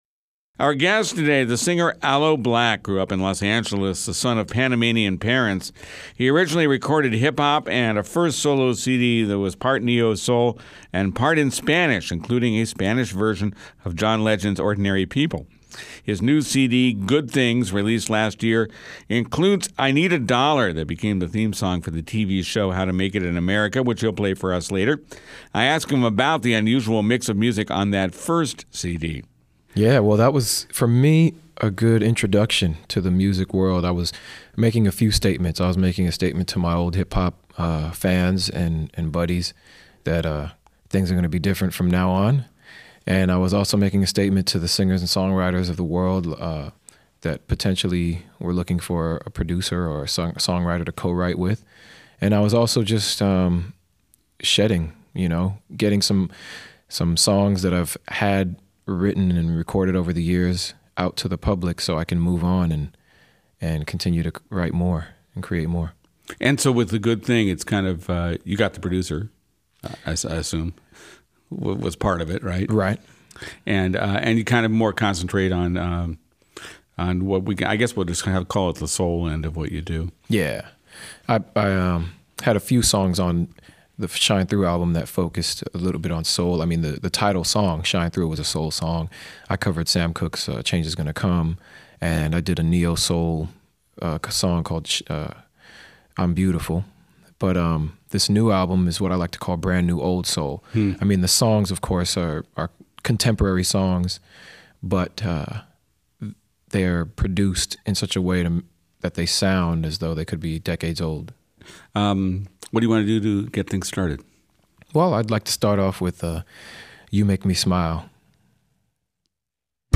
soul crooner